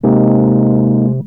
03 Rhodes 3.wav